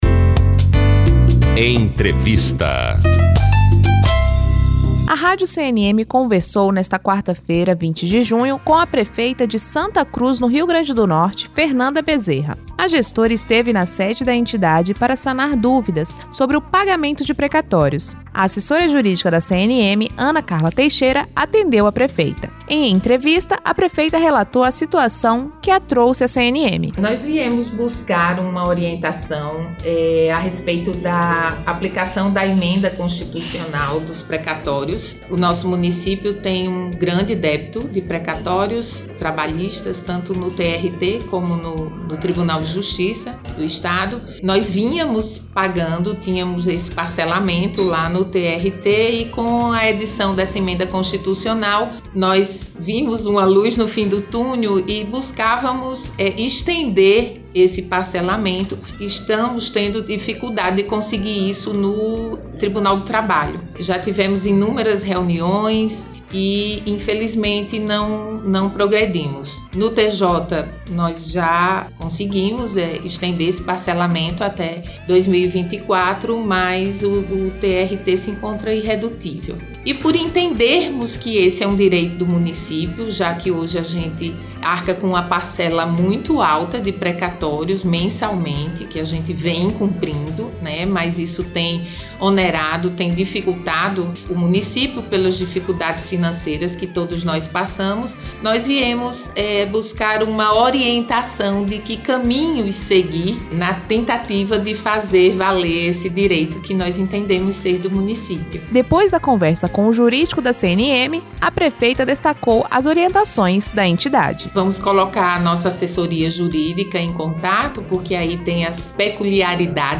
Entrevista: prefeita de Santa Cruz (RN), Fernanda Bezerra
Entrevista-prefeita-de-Santa-Cruz-RN-Fernanda-Bezerra.mp3